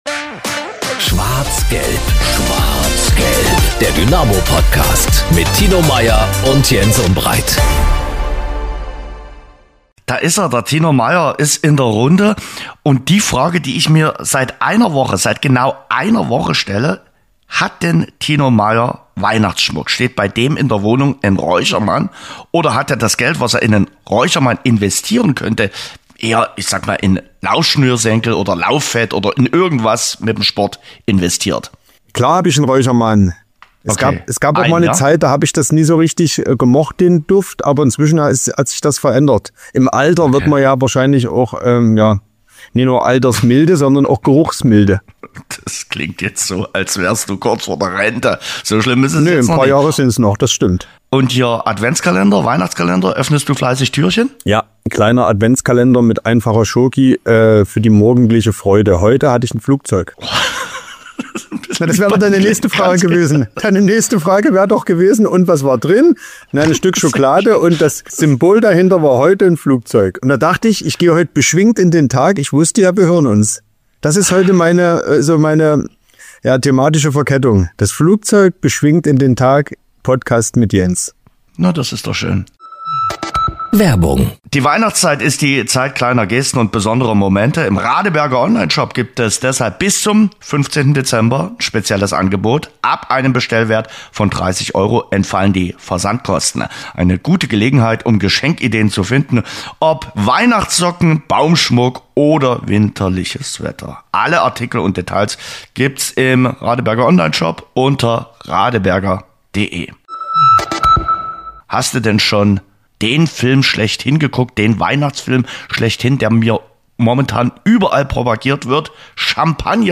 Im Mittelpunkt der Folge steht das ausführliche Interview mit Ex-Dynamo Pascal Testroet, der über die SGD und seine Sicht auf die 2. Bundesliga spricht. Dazu gibt es Stimmen von Alexander Rossipal, Vincent Vermeij und Niklas Hauptmann sowie den Blick auf die kommende Aufgabe in Kaiserslautern.